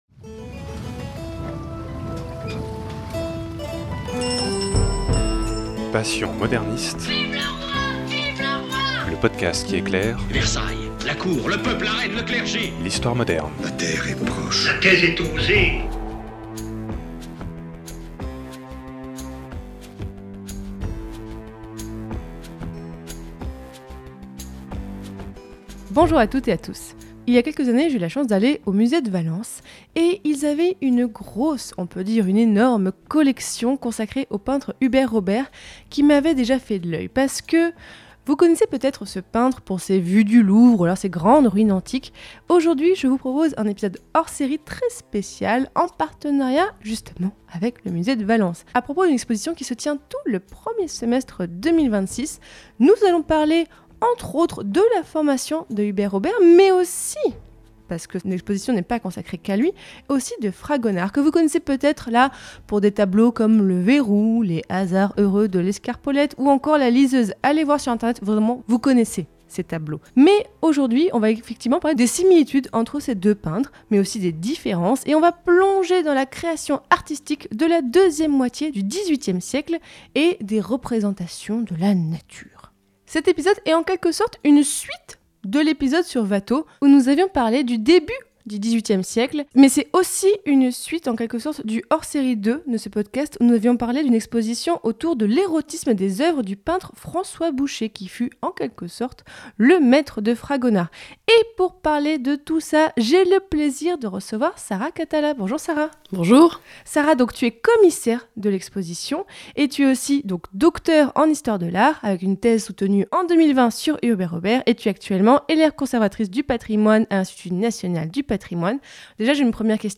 Découvrez les peintres Hubert Robert et Fragonard et leur rapport à la nature dans cette interview à l'occasion d'une exposition au Musée de Valence !